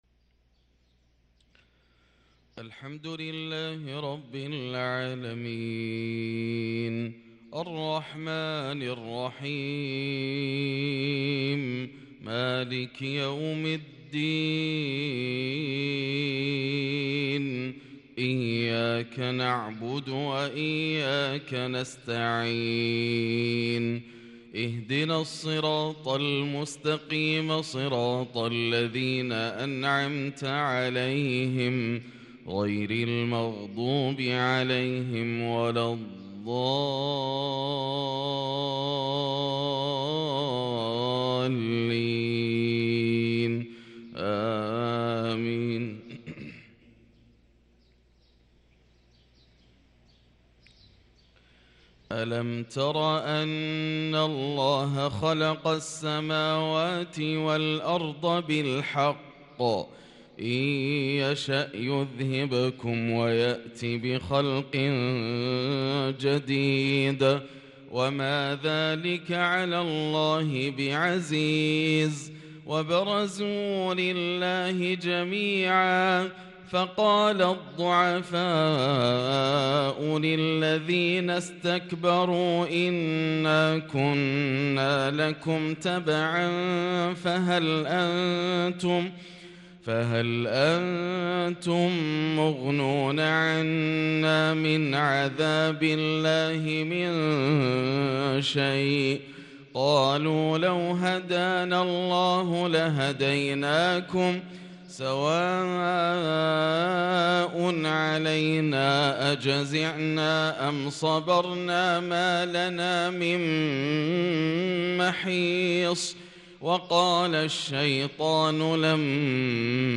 صلاة الفجر للقارئ ياسر الدوسري 27 ربيع الأول 1444 هـ
تِلَاوَات الْحَرَمَيْن .